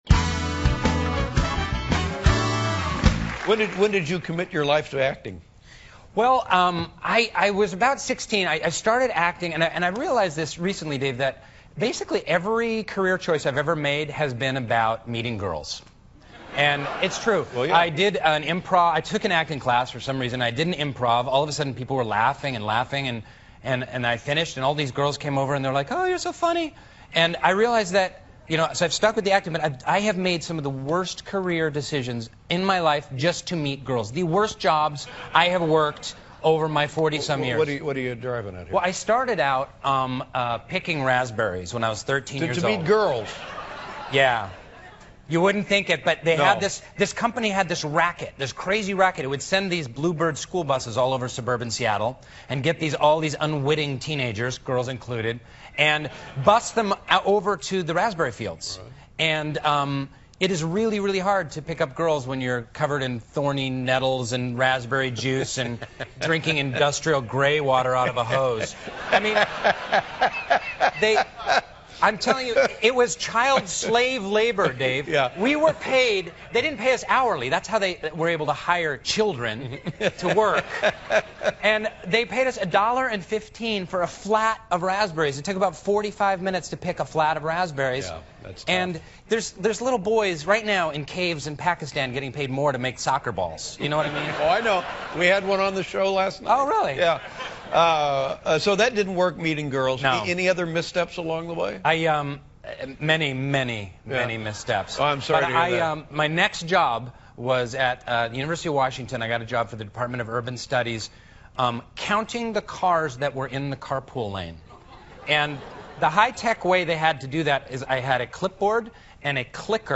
访谈录 2011-02-27&03-01 相约雷恩·威尔森 听力文件下载—在线英语听力室